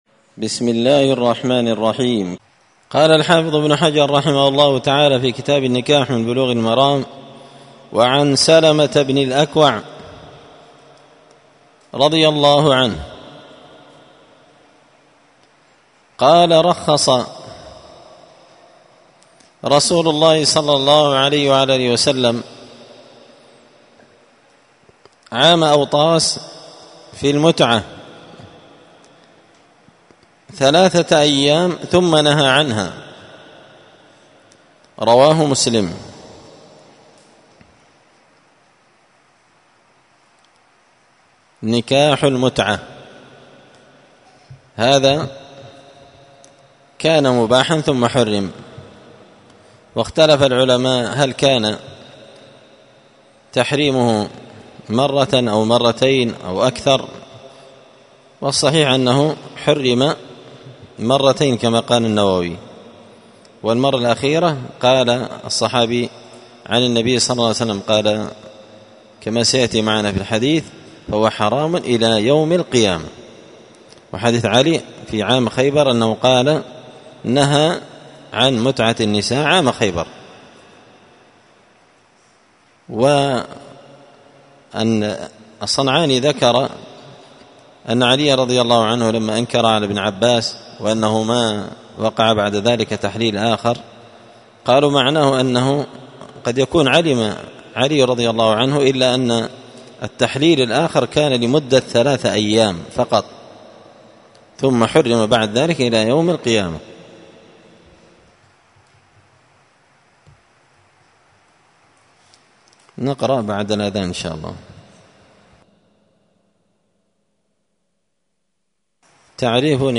كتاب النكاح من سبل السلام شرح بلوغ المرام لابن الأمير الصنعاني رحمه الله تعالى الدرس – 13 تابع أحكام النكاح